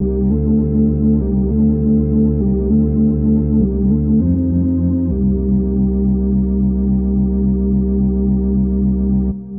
Tag: 100 bpm Chill Out Loops Organ Loops 1.21 MB wav Key : Unknown